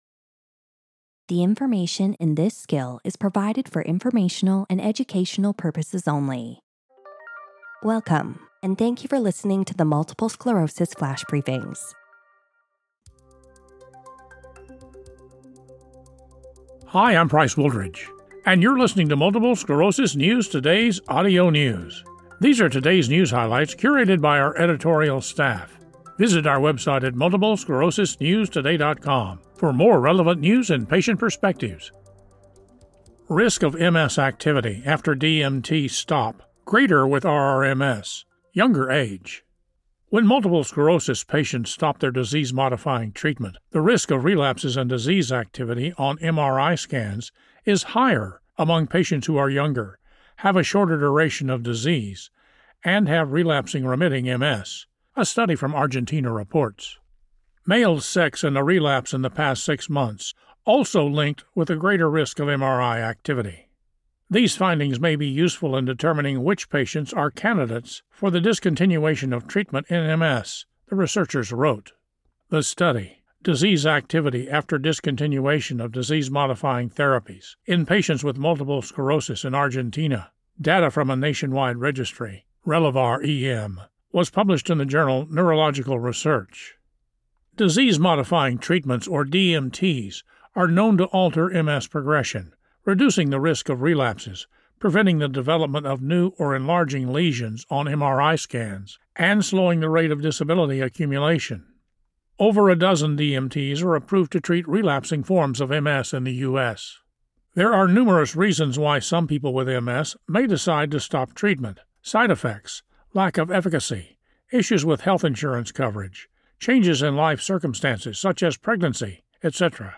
reads a news article about a study showing that when MS patients stop their disease-modifying treatment, the risk of relapses and disease activity on MRI scans is higher among patients who have RRMS.